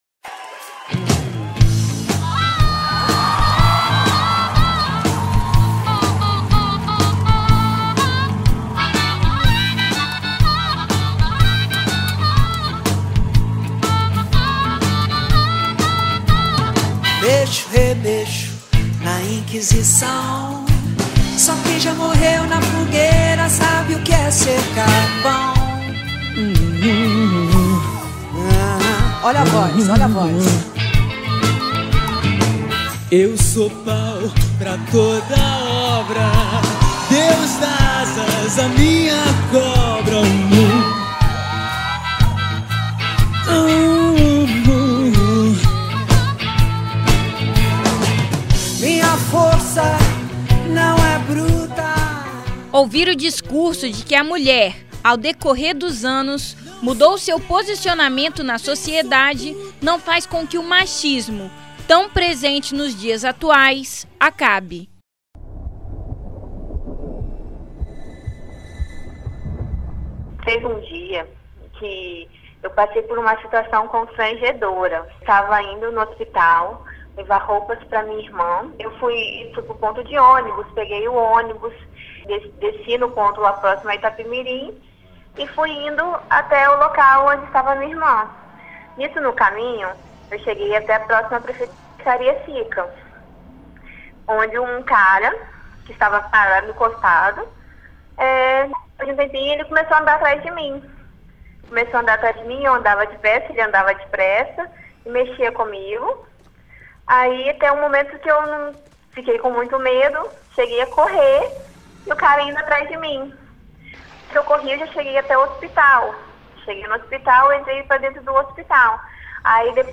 Confira a matéria completa que foi ao ar no programa Revista Universitária!